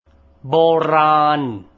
борАн